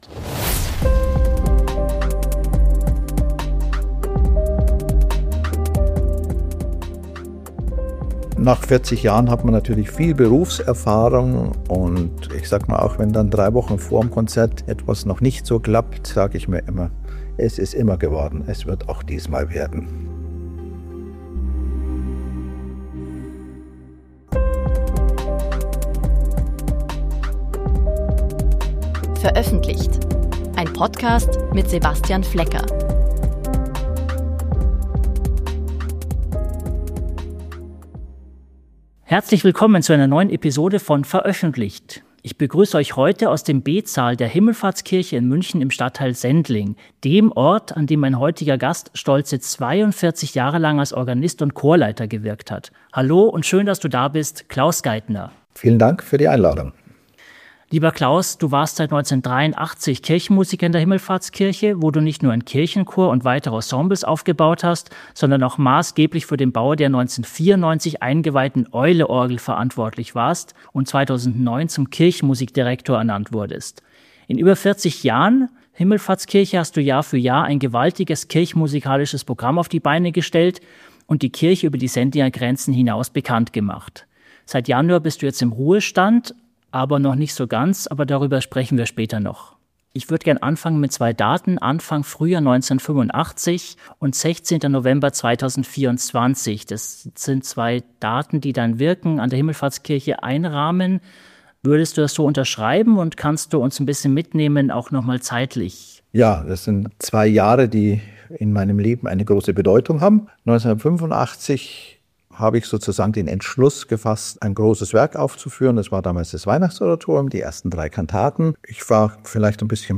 Ein Gespräch über Kreativität im Kantorat, Selbstzweifel, große und neue Projekte.